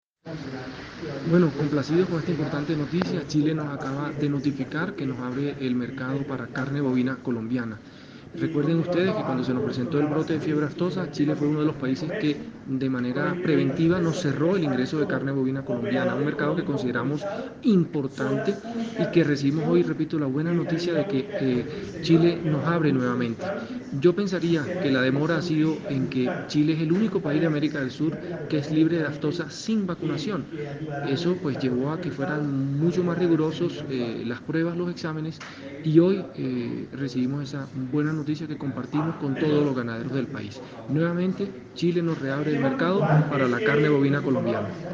Declaraciones-Gerente-General-del-ICA_2.mp3